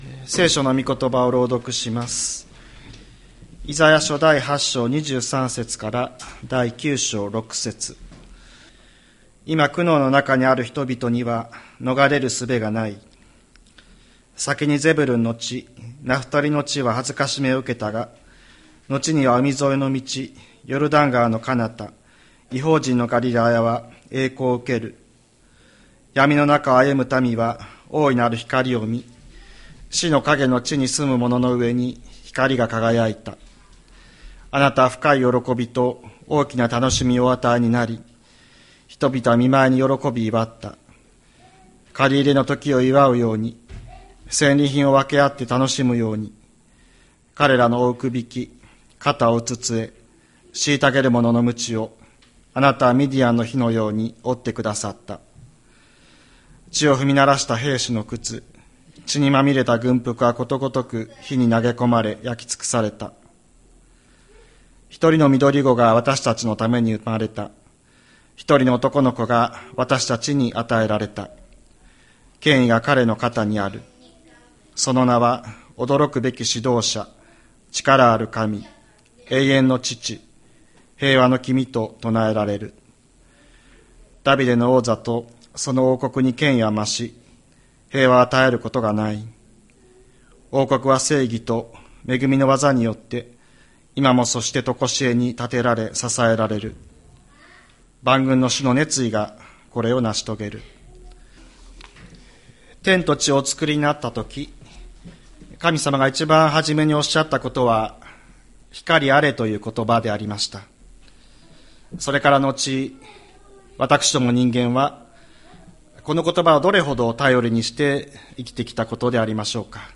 千里山教会 2024年12月15日の礼拝メッセージ。